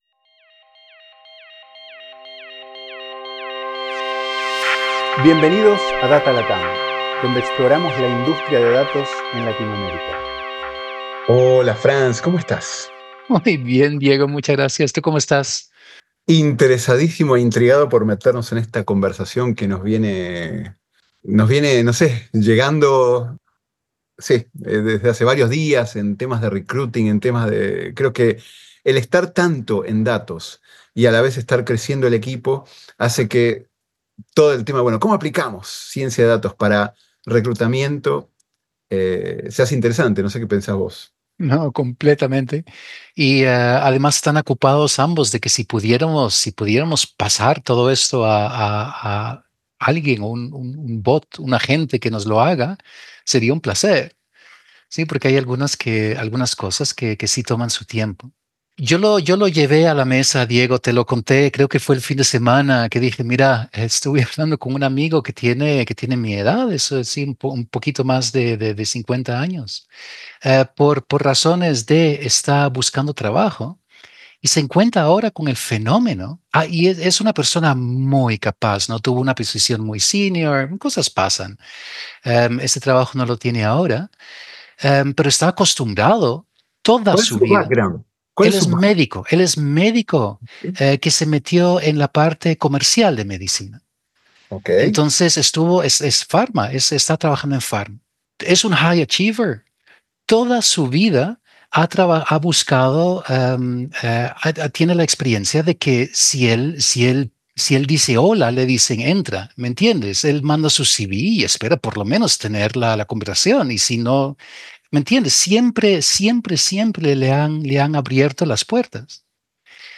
Categorías: Tertulia | Lenguaje: Español